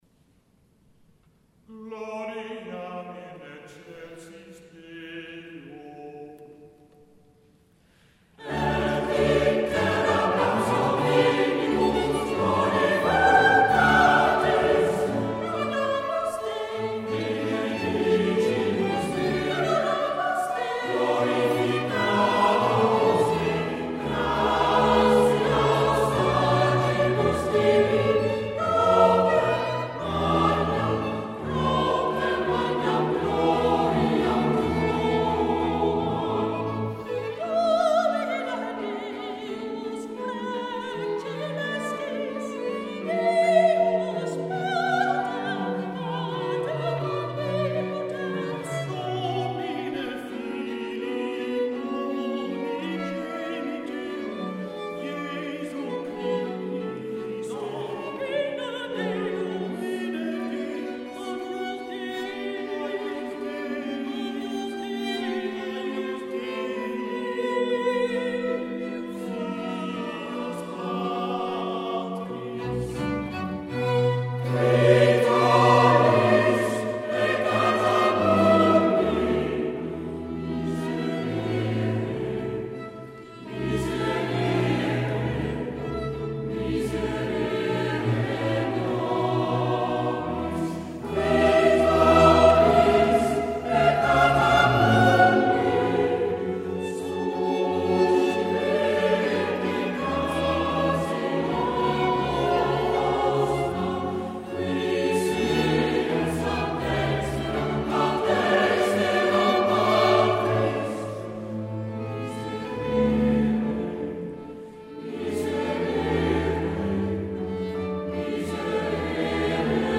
Op deze pagina zijn diverse live opnamen te horen,
...geen studio kwaliteit...
live opname van andere concerten: